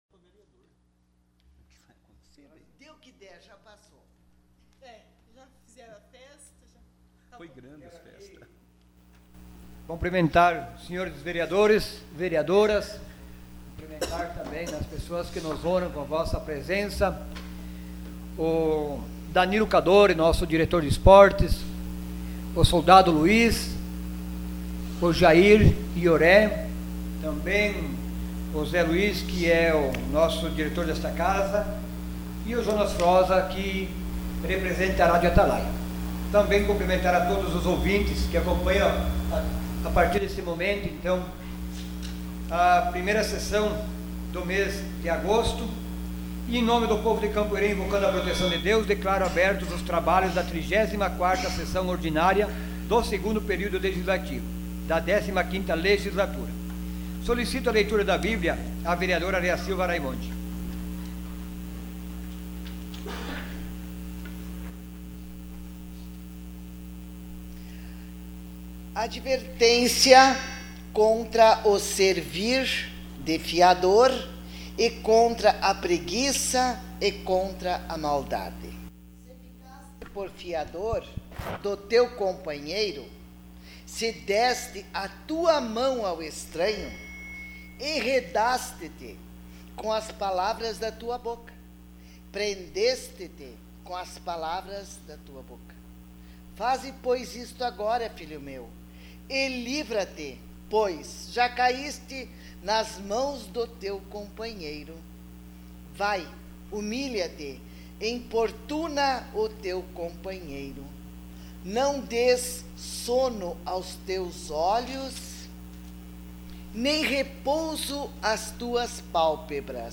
Sessão Ordinária dia 06 de agosto de 2018.